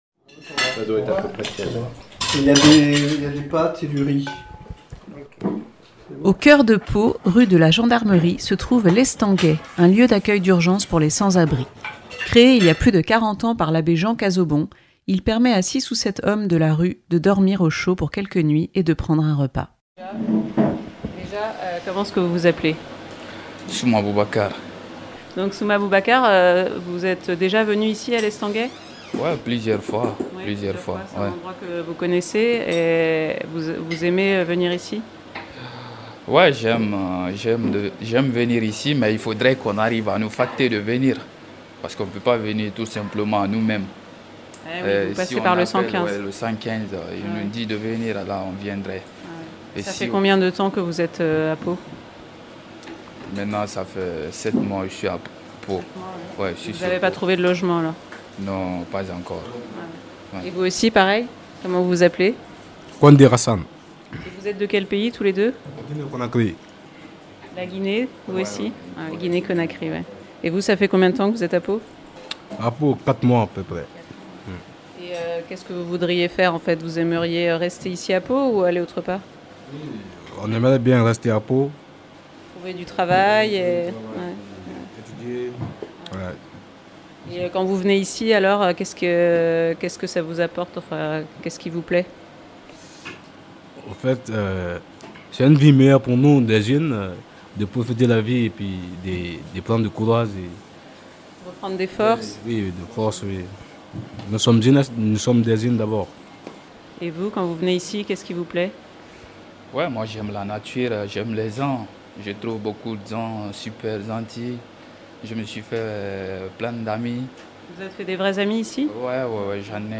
Reportage.